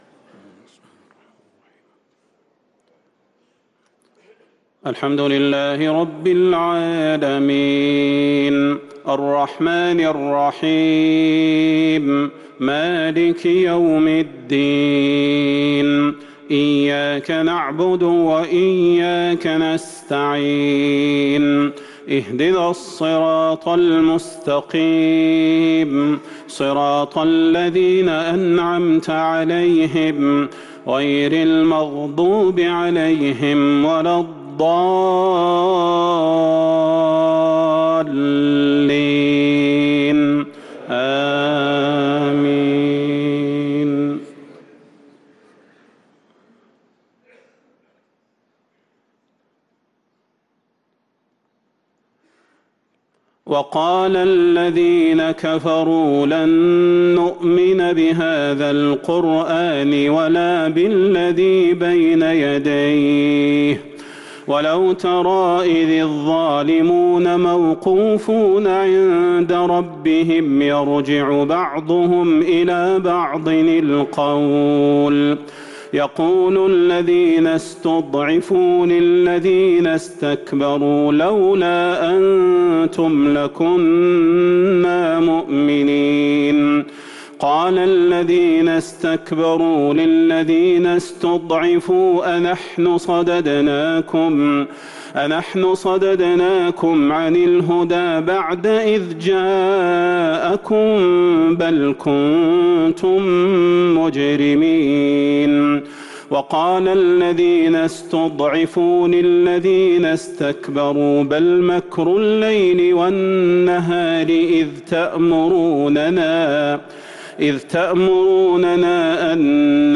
صلاة المغرب للقارئ صلاح البدير 16 ربيع الآخر 1444 هـ
تِلَاوَات الْحَرَمَيْن .